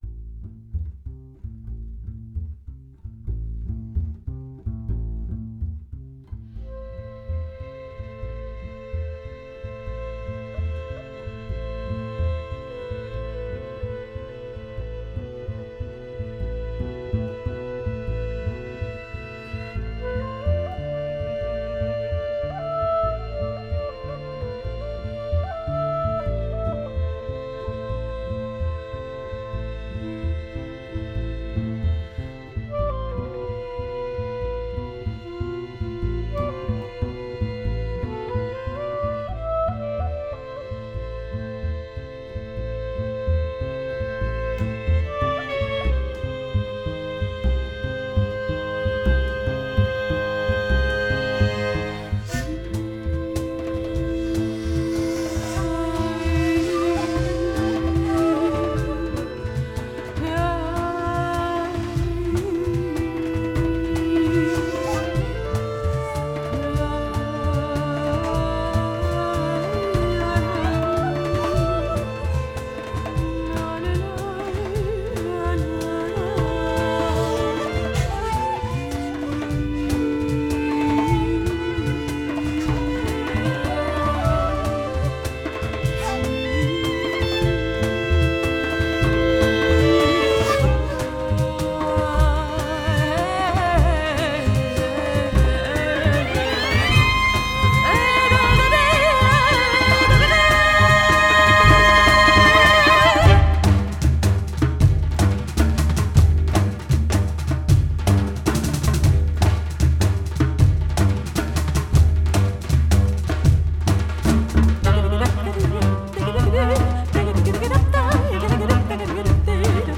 Жанр: Classical.